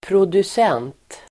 Uttal: [produs'en:t]